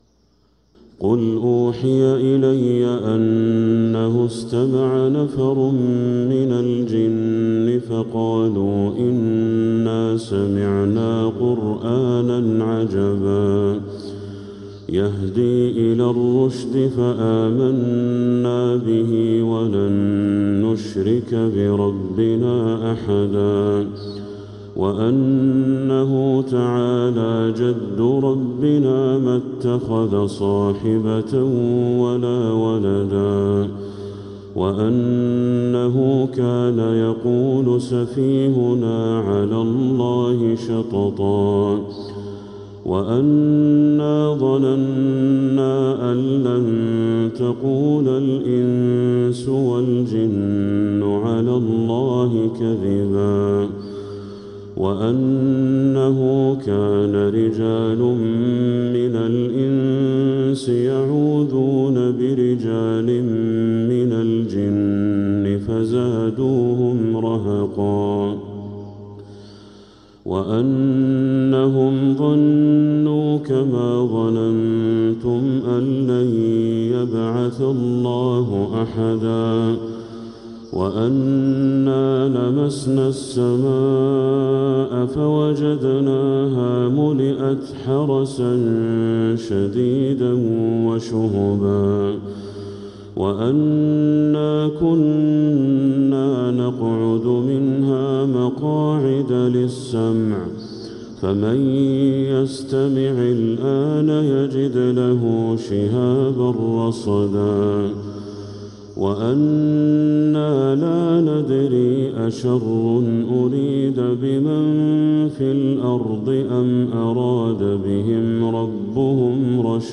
سورة الجن كاملة | فجر6 عام 1446هـ > السور المكتملة للشيخ بدر التركي من الحرم المكي 🕋 > السور المكتملة 🕋 > المزيد - تلاوات الحرمين